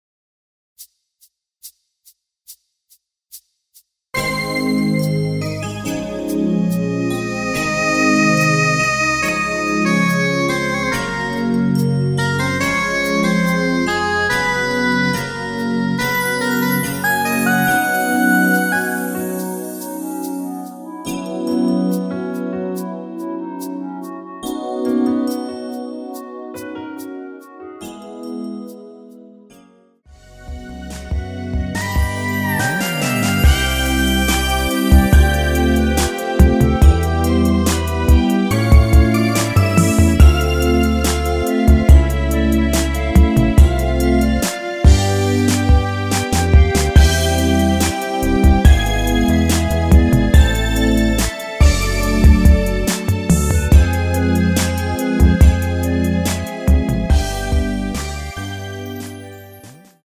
(-2) 내린 멜로디 포함된 MR 입니다.(미리듣기 참조)
앞부분30초, 뒷부분30초씩 편집해서 올려 드리고 있습니다.
중간에 음이 끈어지고 다시 나오는 이유는
(멜로디 MR)은 가이드 멜로디가 포함된 MR 입니다.